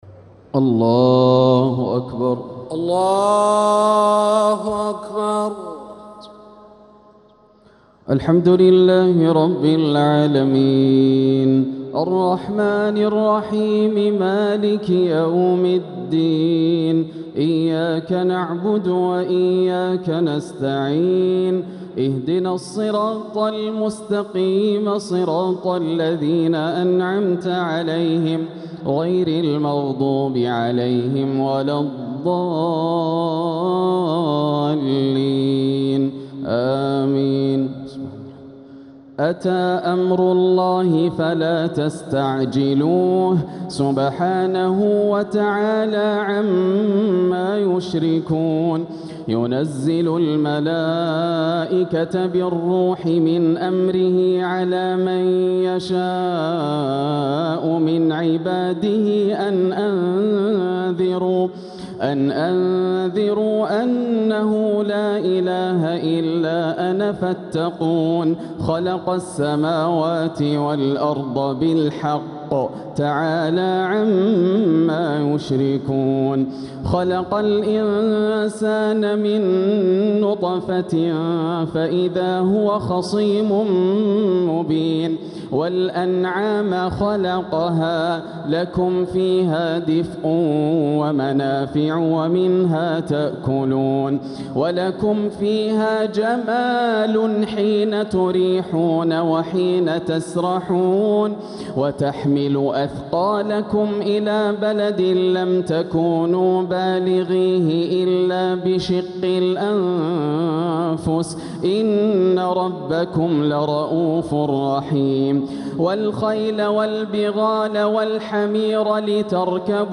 تلاوة لفواتح سورة النحل (1-55) | تراويح ليلة 18 رمضان 1447هـ > الليالي الكاملة > رمضان 1447 هـ > التراويح - تلاوات ياسر الدوسري